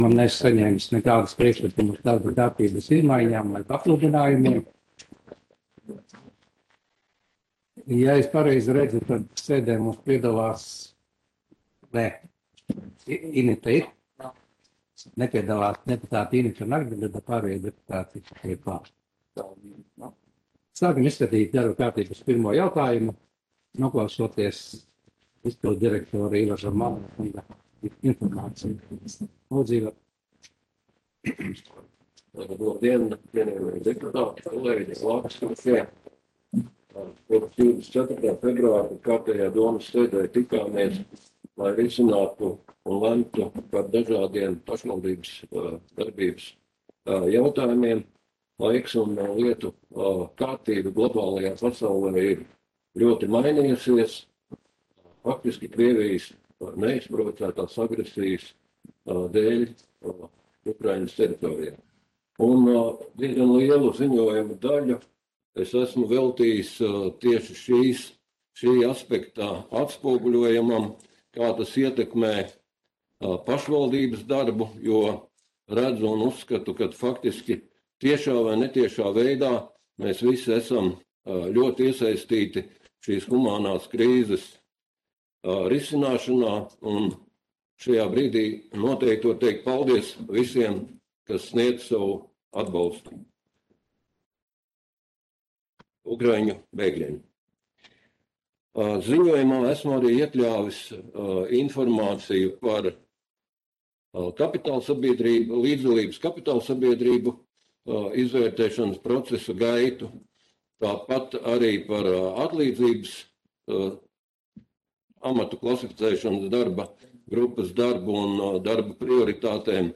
Audioieraksts - 2022.gada 31.marta domes sēde